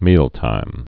(mēltīm)